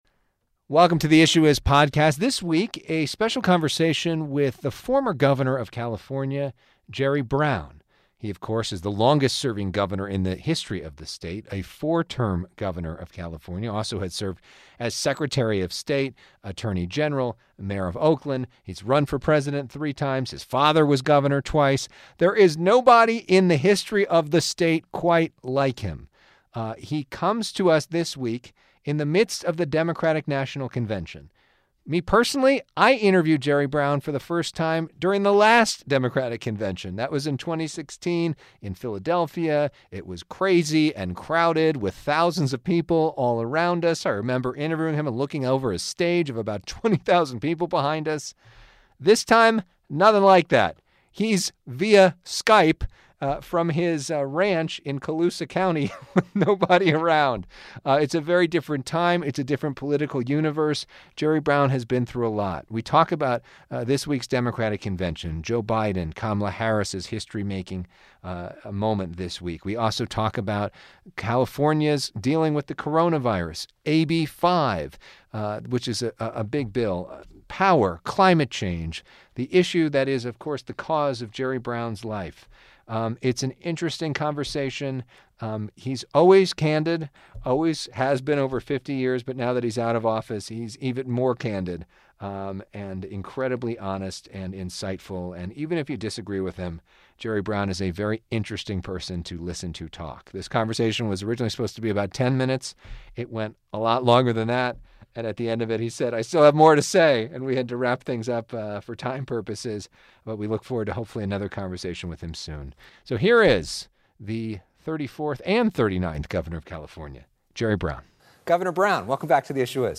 154: A conversation with former CA Gov. Jerry Brown